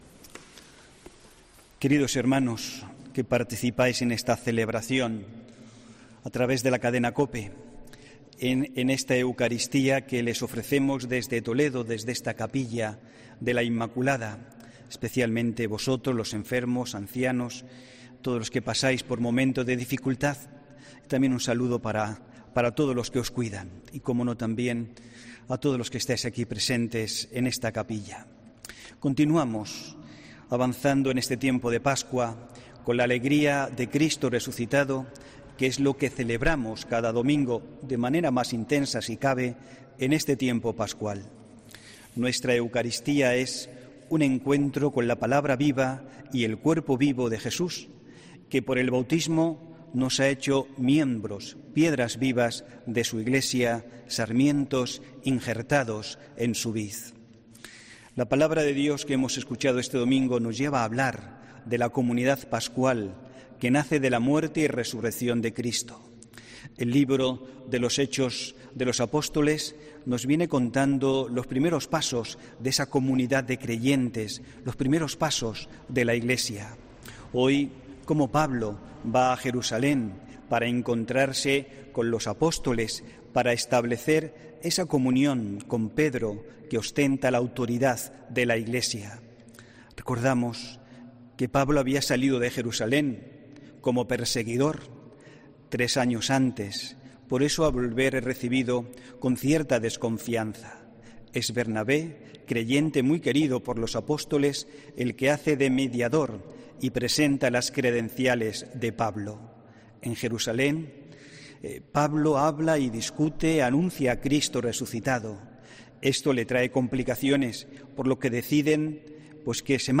HOMILÍA 2 DE MAYO 2021